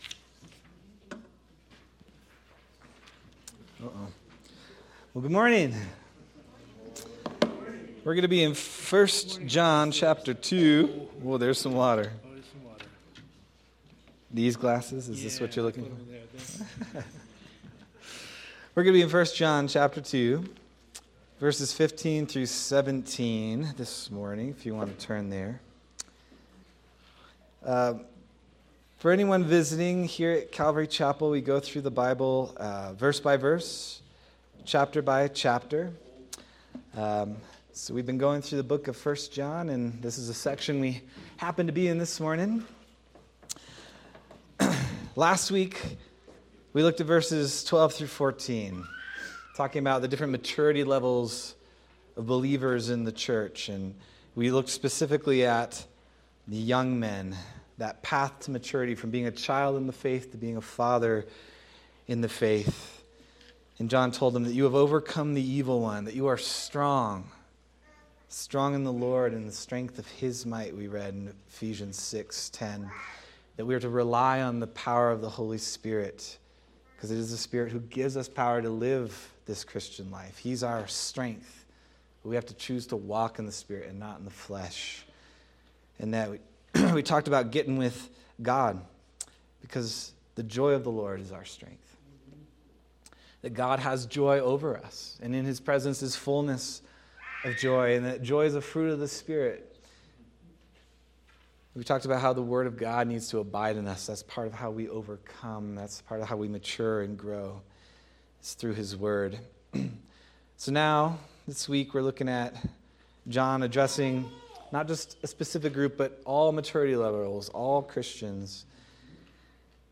February 15th, 2026 Sermon